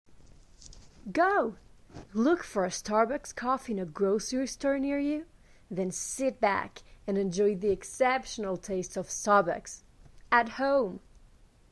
Voix OFF - English - Starbucks at home
25 - 50 ans - Contralto